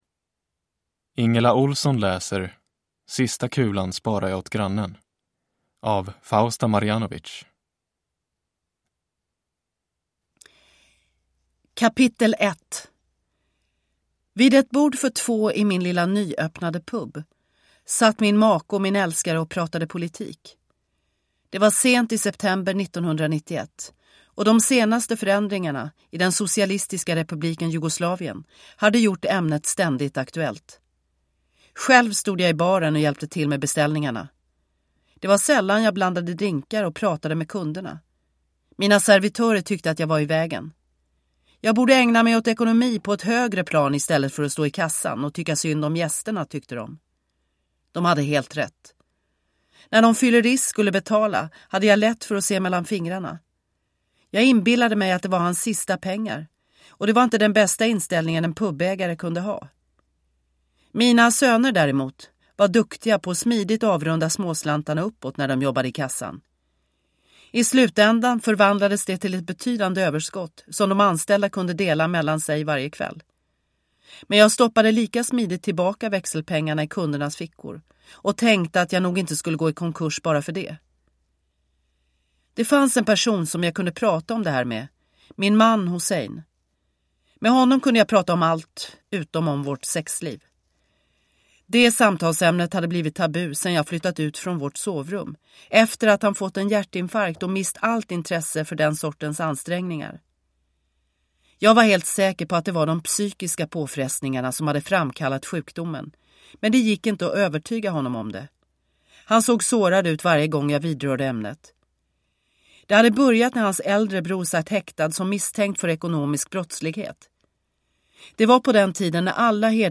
Sista kulan sparar jag åt grannen – Ljudbok – Laddas ner